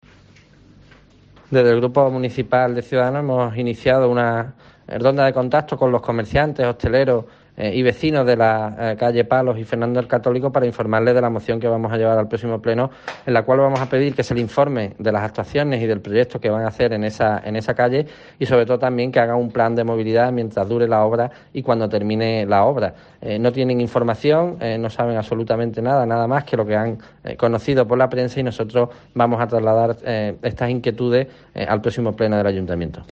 Guillermo García de Longoria, portavoz de Ciudadanos en Huelva